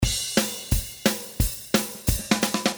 This package contains real drum loops in the style of the famous song "I'm Still Standing".
The drum loops are in 175 BPM and there are 25 loops in total.
The package contains loops with close hihat ,half open hihat ,ride cymbal loops and